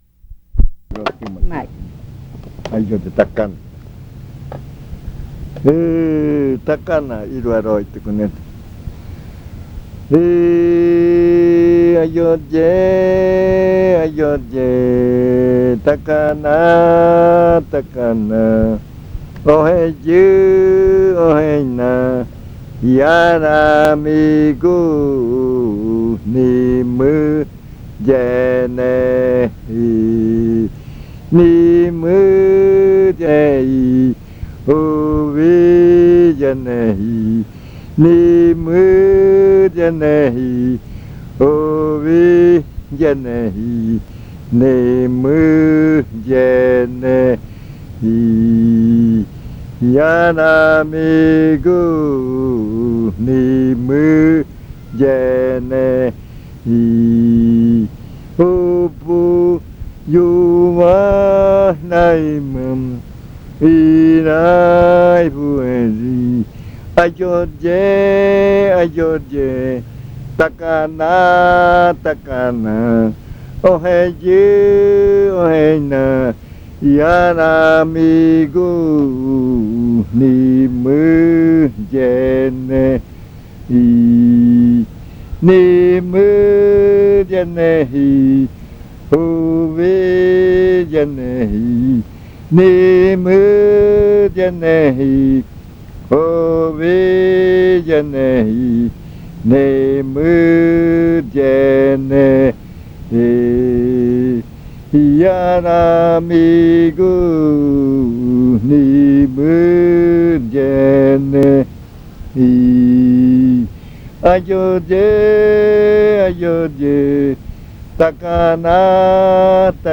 Leticia, Amazonas
Canto dedicado a un rio grande.
Chant dedicated to a large river. In this case, the singer dedicates it to the Tacana river.